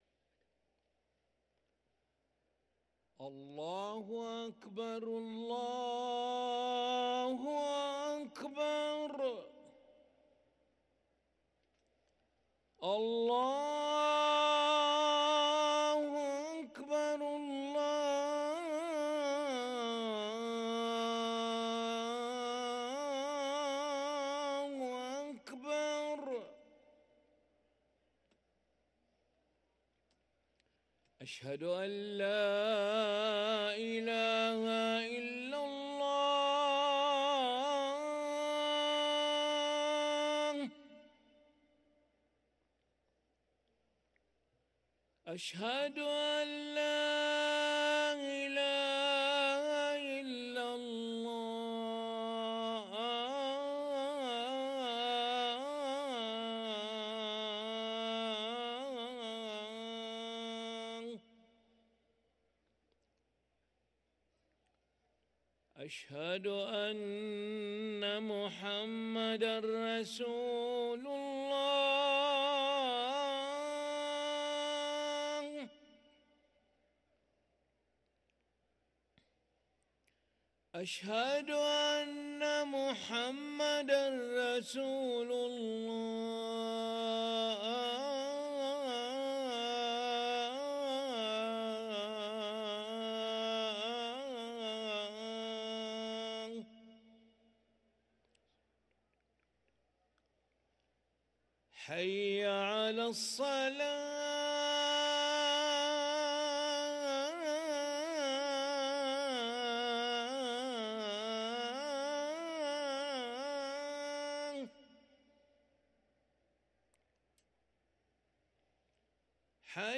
أذان العشاء للمؤذن علي ملا الخميس 26 جمادى الآخرة 1444هـ > ١٤٤٤ 🕋 > ركن الأذان 🕋 > المزيد - تلاوات الحرمين